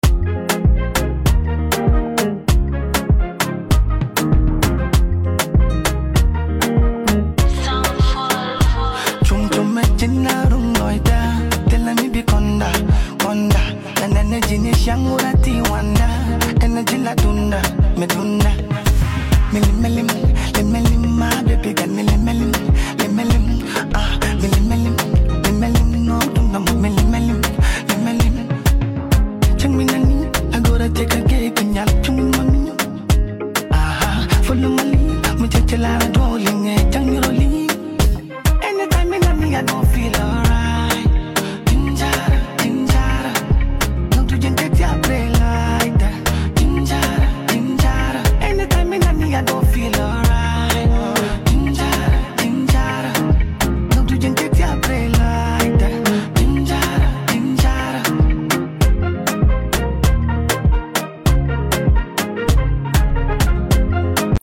emotionally charged project
With its infectious beat and captivating vocals